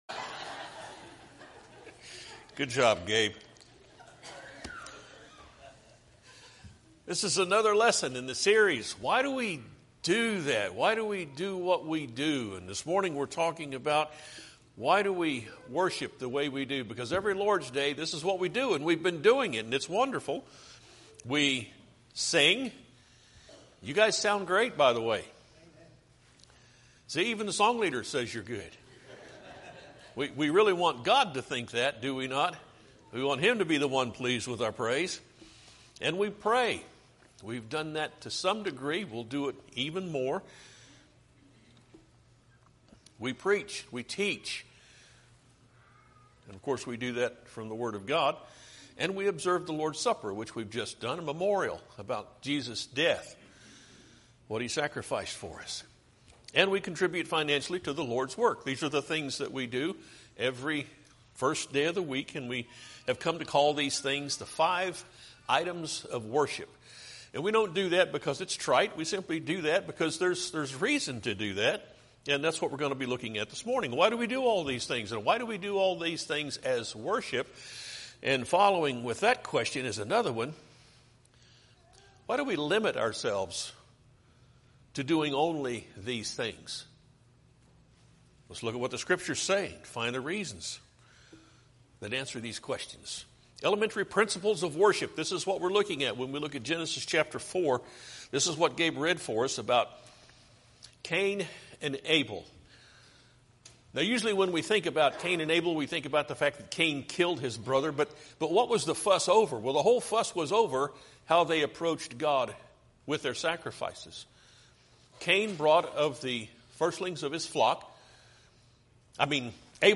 Podcasts Videos Series Sermons Why Do We Worship God?